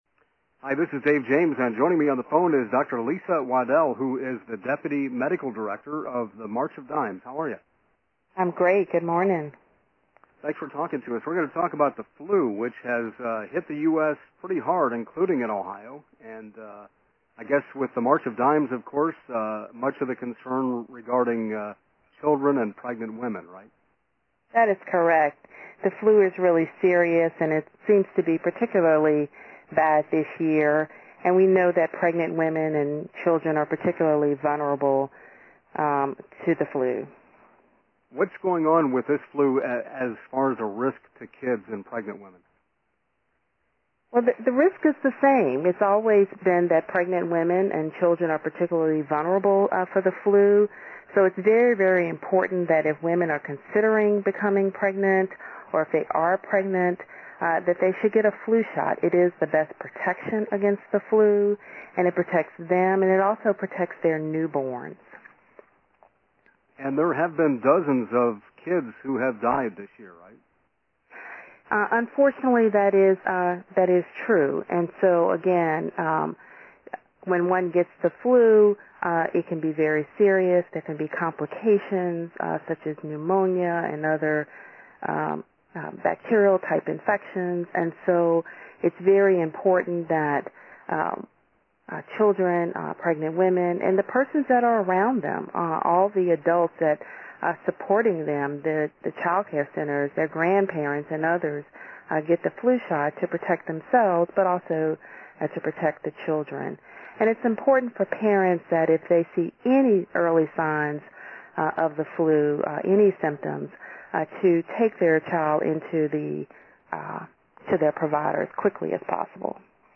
Radio interviews:
7:00 am: five minute taped interview on the Ohio Radio News Network (OH).